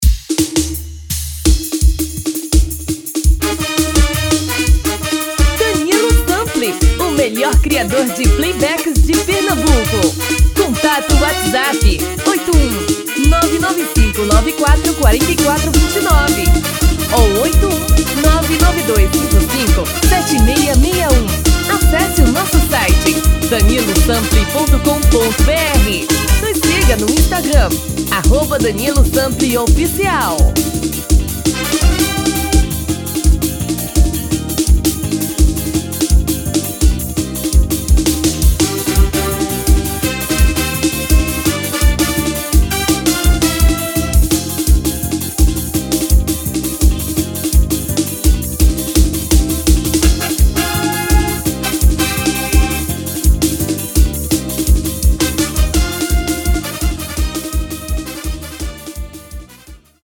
TOM FEMININO